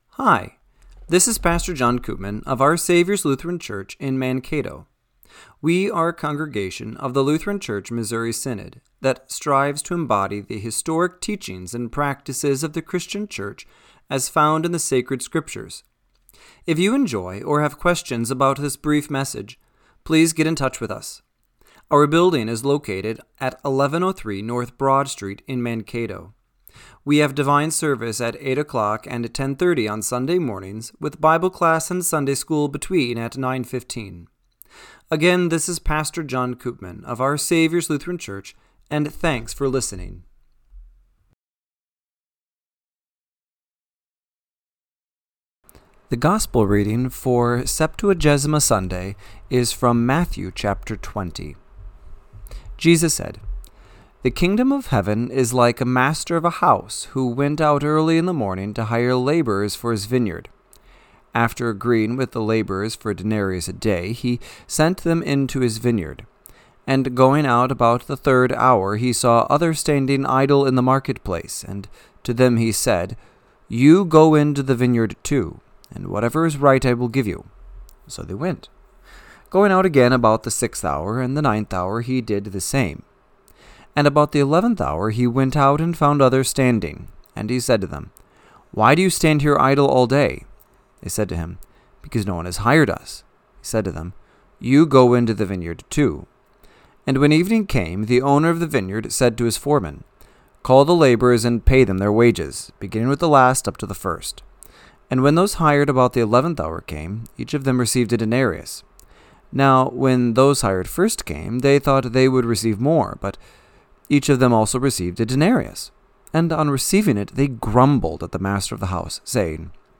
Radio-Matins-2-1-26.mp3